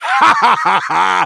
bull_kill_vo_02.wav